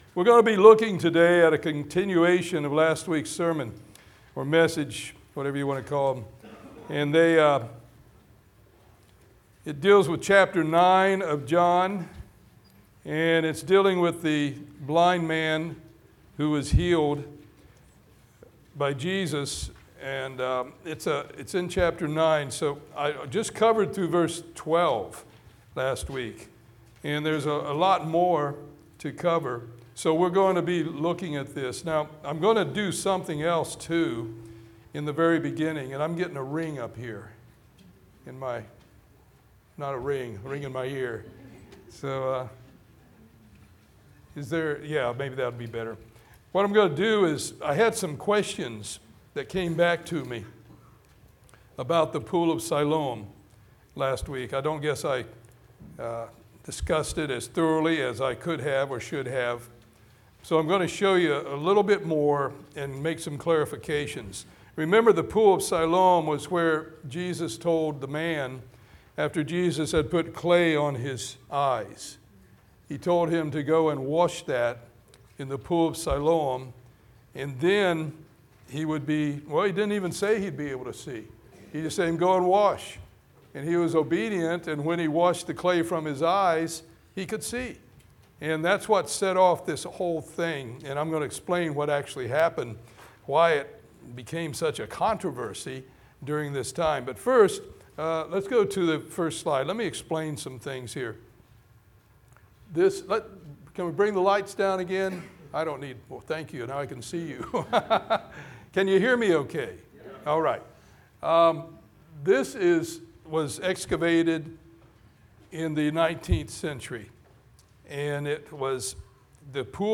Today we will learn what ultimately happened to the blind man after Jesus healed him as he gained new sight spiritually and the reactions of those around him. Sermon Notes:Coming soon.